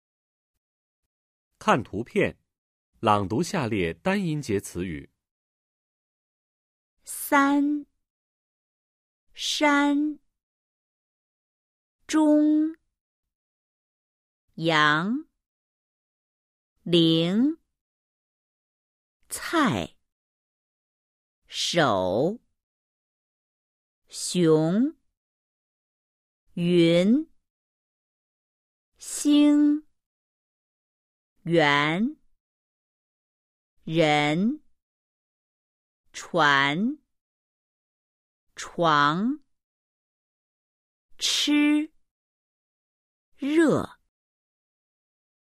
Sau đây là các từ có một âm tiết.